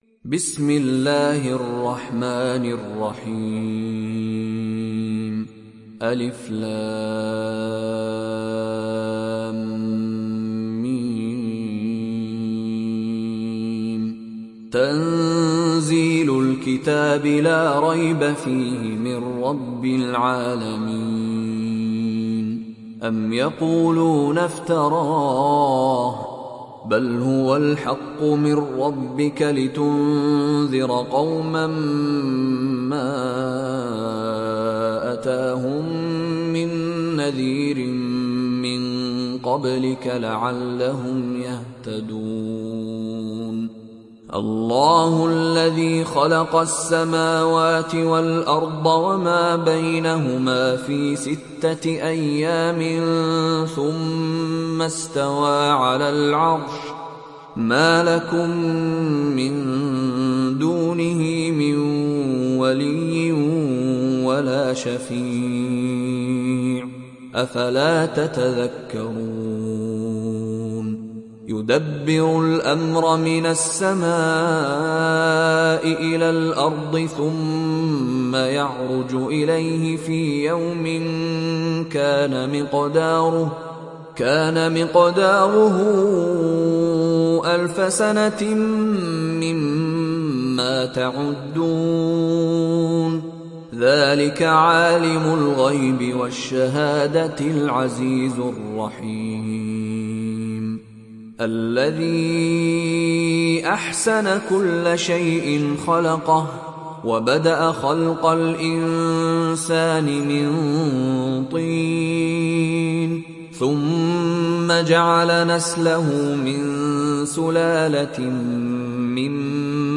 تحميل سورة السجدة mp3 بصوت مشاري راشد العفاسي برواية حفص عن عاصم, تحميل استماع القرآن الكريم على الجوال mp3 كاملا بروابط مباشرة وسريعة